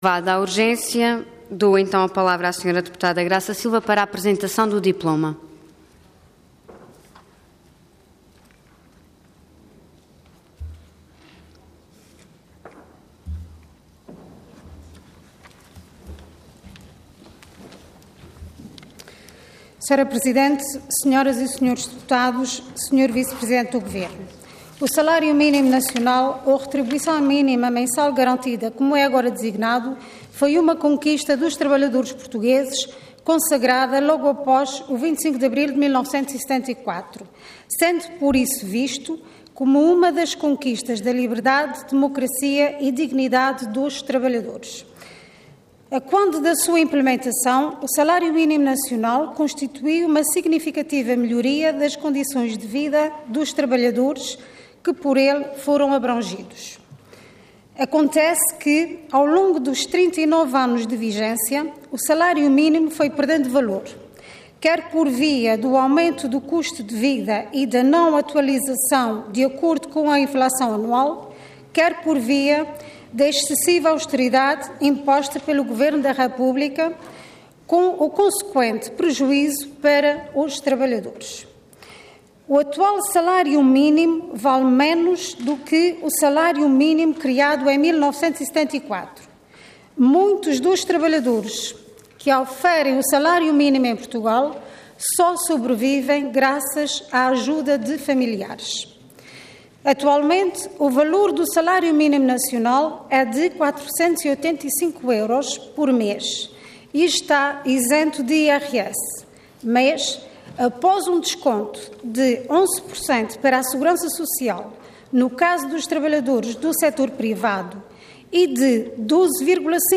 Detalhe de vídeo 12 de julho de 2013 Download áudio Download vídeo Diário da Sessão Processo X Legislatura Aumento da retribuição mínima mensal garantida. Intervenção Anteproposta de Lei Orador Graça Silva Cargo Deputada Entidade PS